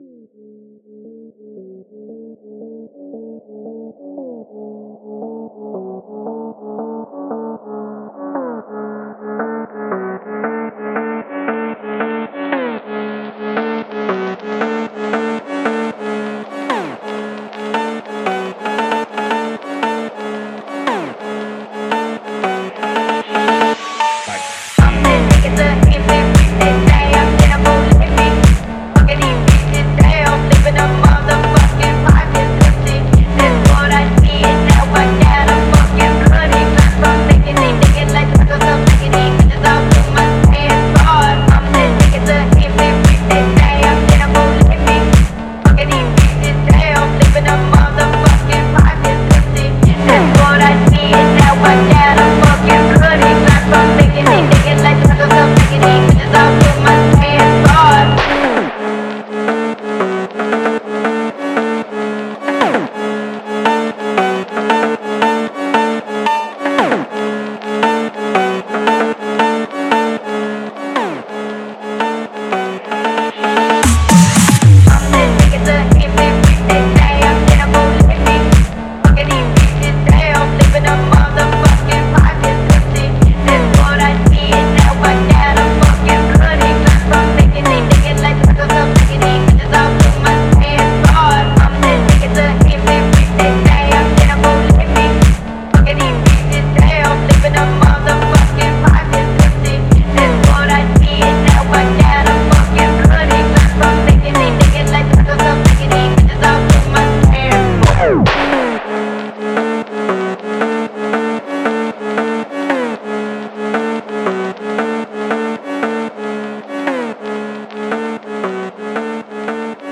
SEISMIC_TOSS.mp3 also is this beat trash or is it good?